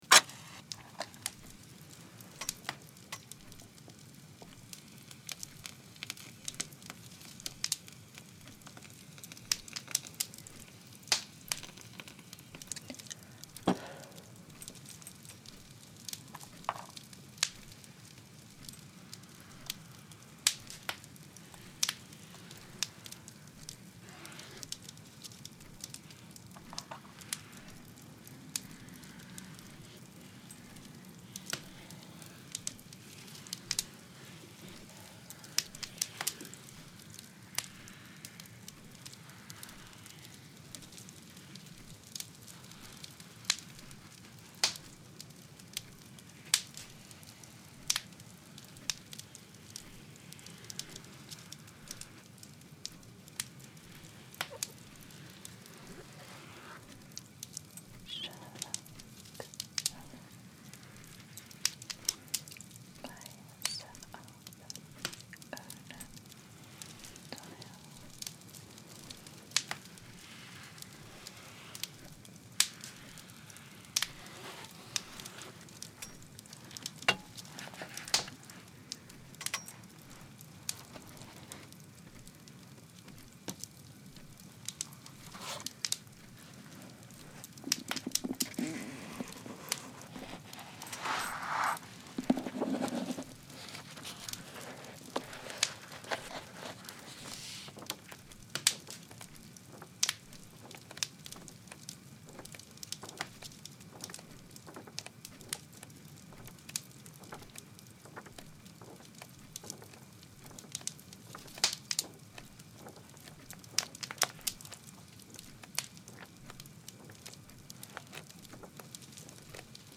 Sherlock Holmes - Unintelligible Whispered Reading (WITHOUT ambient sounds)